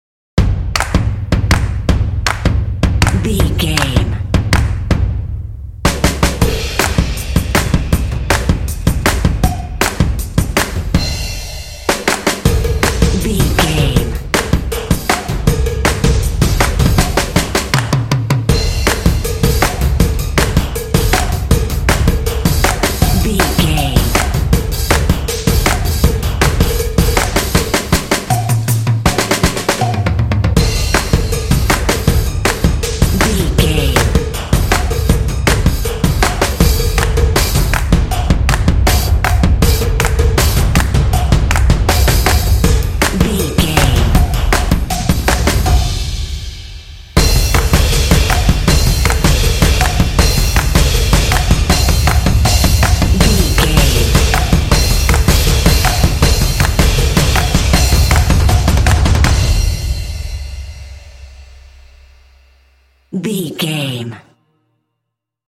Epic / Action
Atonal
driving
motivational
determined
drums
drumline